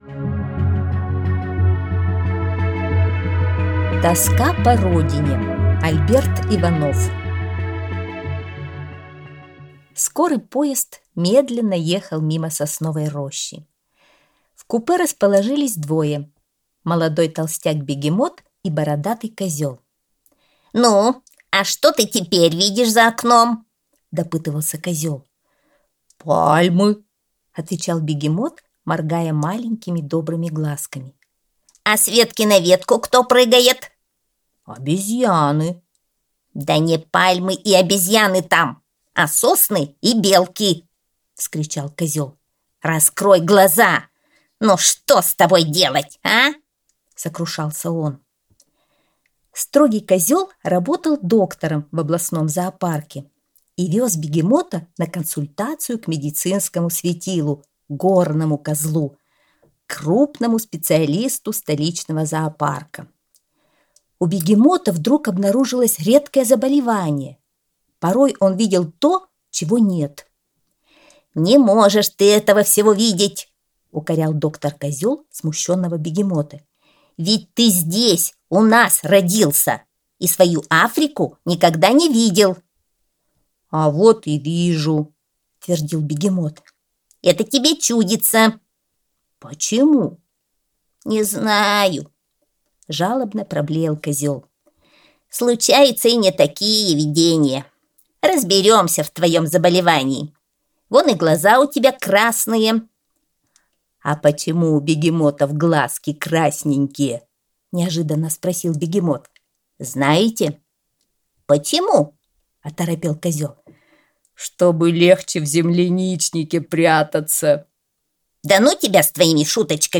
Тоска по Родине - аудиосказка Альберта Иванова - слушать онлайн